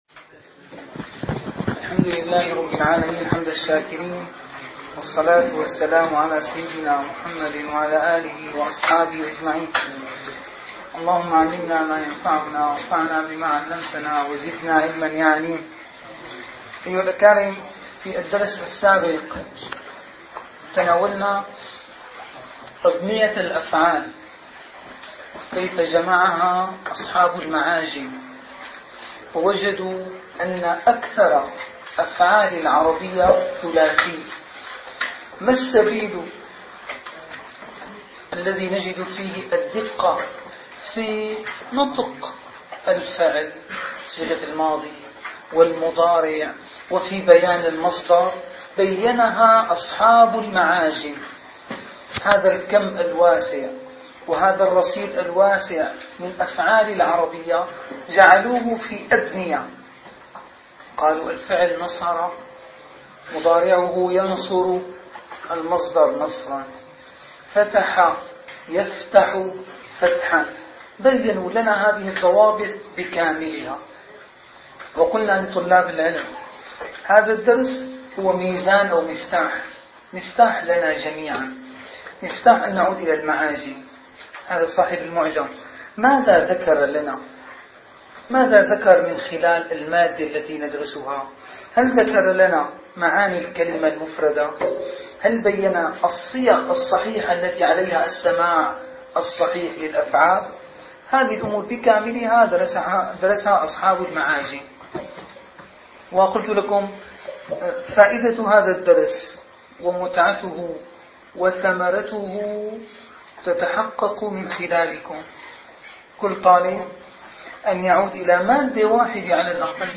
- الدروس العلمية - دورة مفصلة في النحو والإعراب والصرف - الإعراب - الدرس التاسع عشر